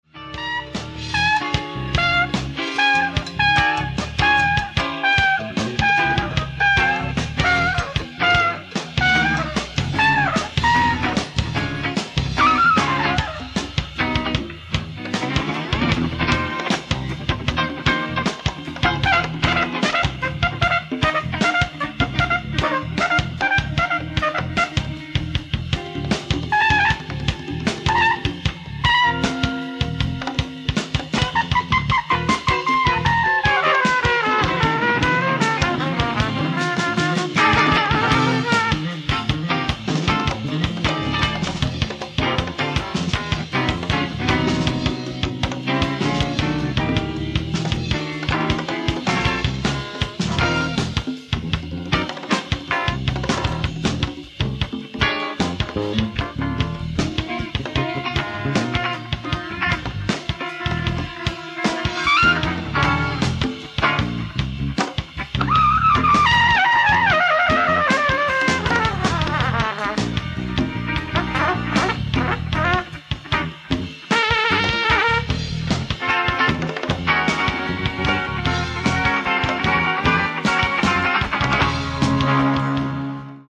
ライブ・アット・ピア ８４、ニューヨーク・シティー、ニューヨーク
※試聴用に実際より音質を落としています。